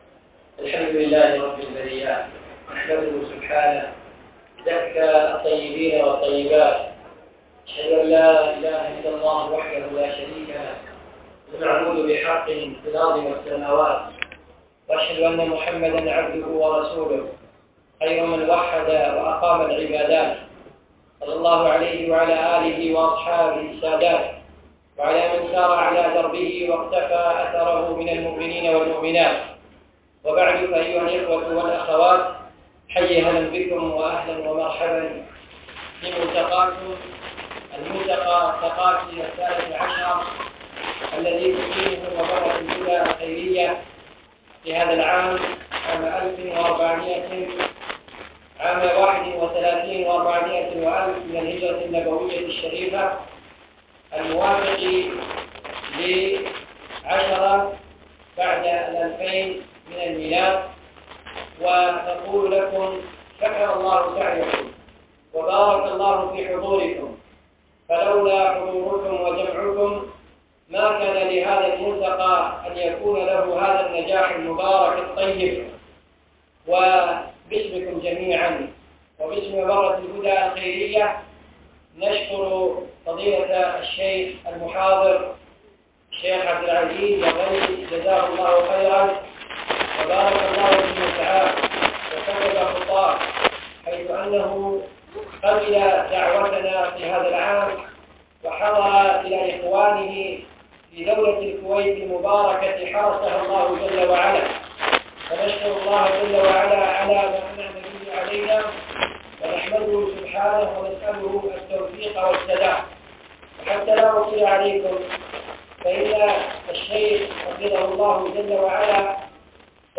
ملتقى الفحيحيل مبرة الهدى عام ١ ٤ ٣ ١